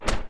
shield-hit-10.ogg